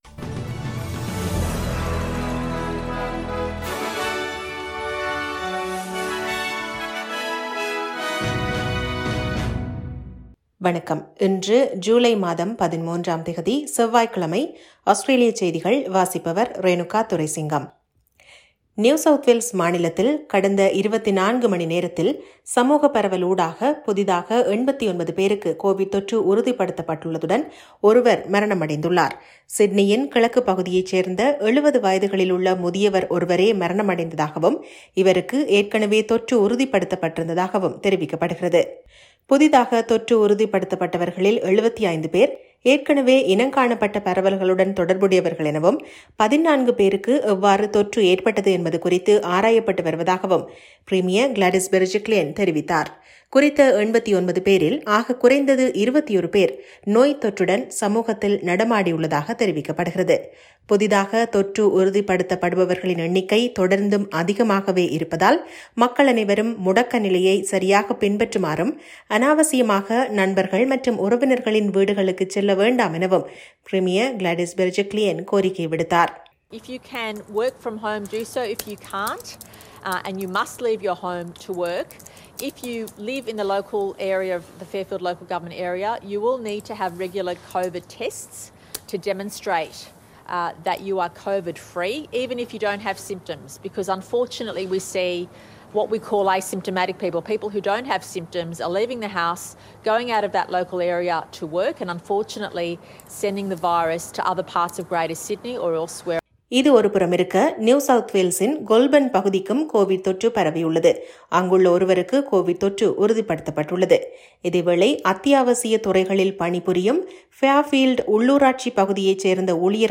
Australian news bulletin for Tuesday 13 July March 2021.